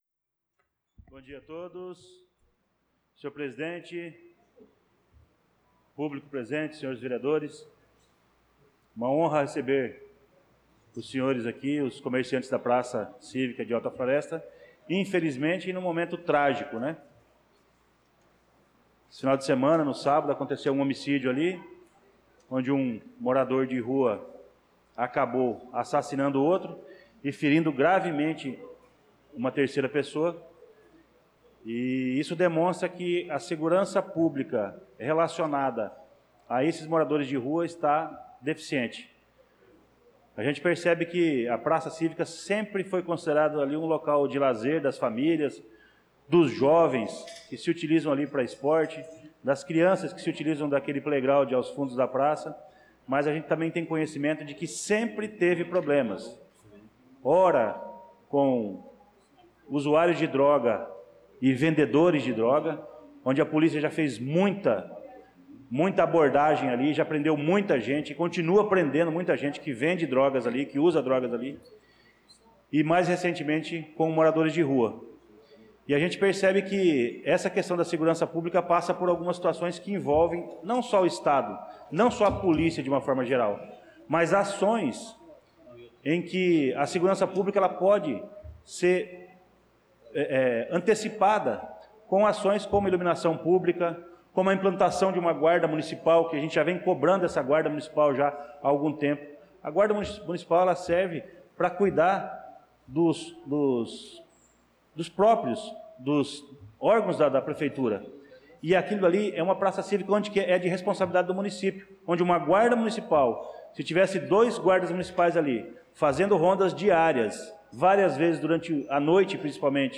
Pronunciamento do vereador Luciano Silva na Sessão Ordinária do dia 02/06/2025